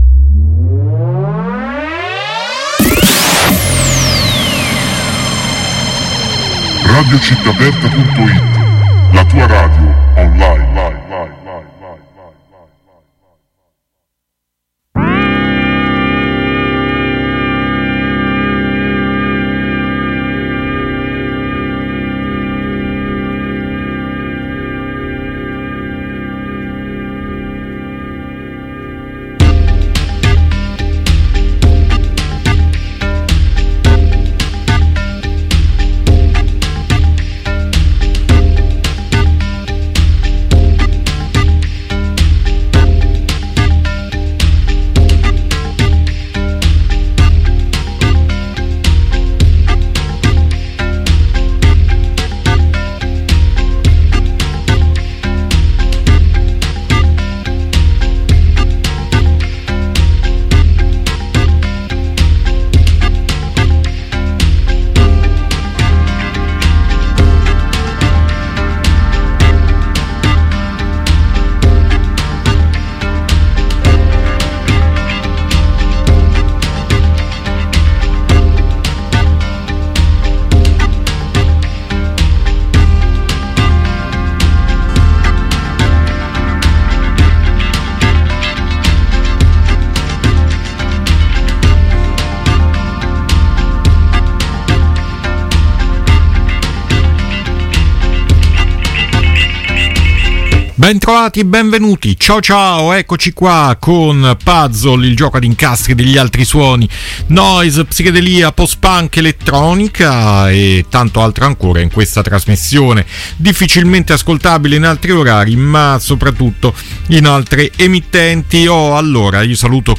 PUZZLE DARK, SLOWCORE E DIGITAL ICE